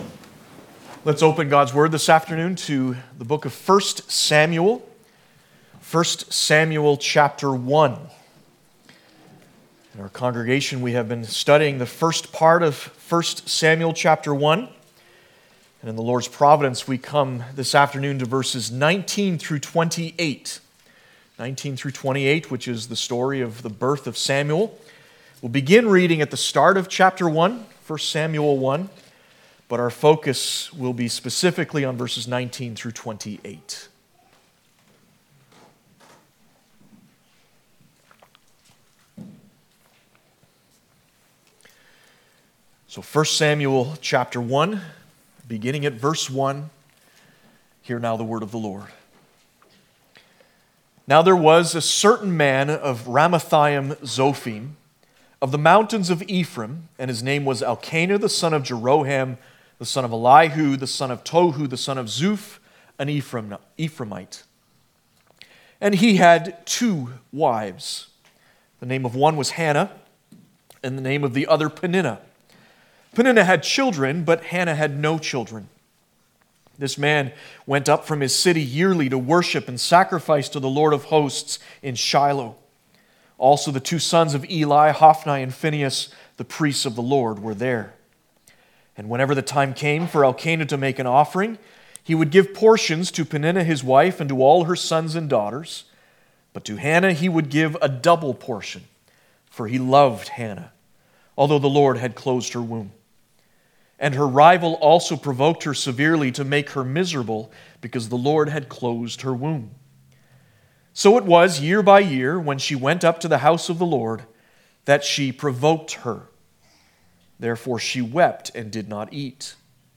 Samuel Passage: 1 Samuel 1:18-28 Service Type: Sunday Afternoon « Abide in Me